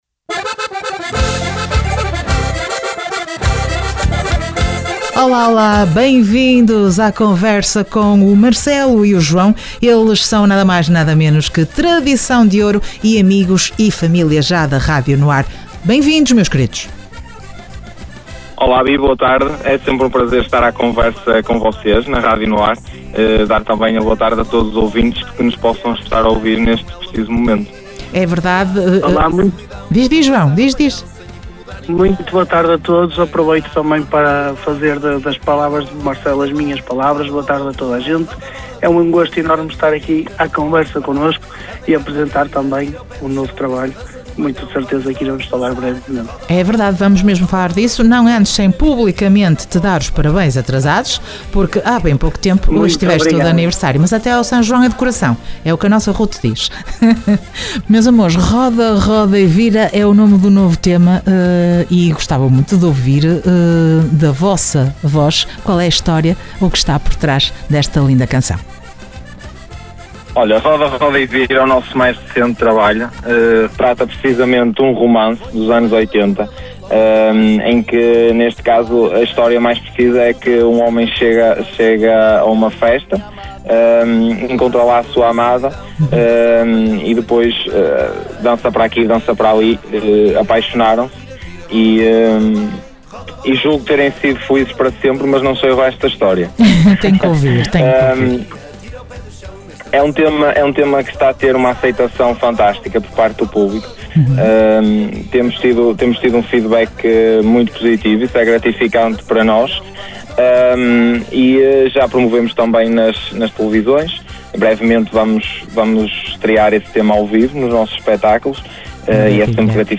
Entrevista Tradição D`ouro dia 21 de Novembro.